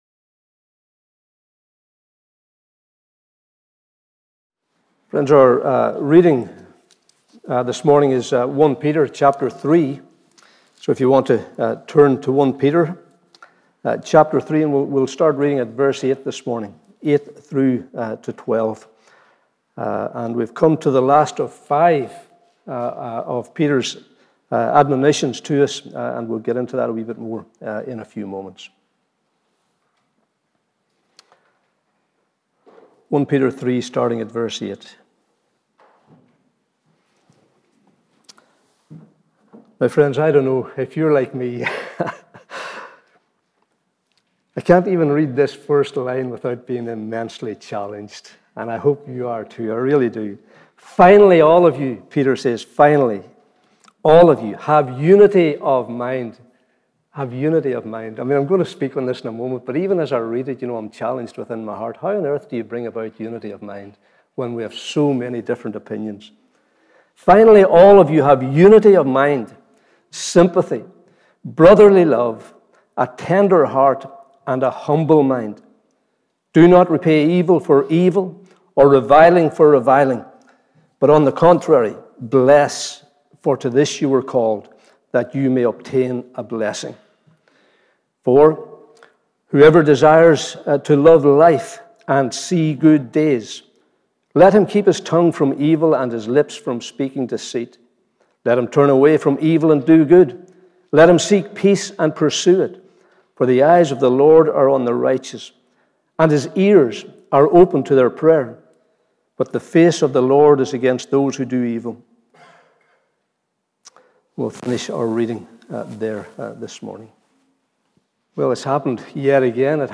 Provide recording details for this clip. Morning Service 24th April 2022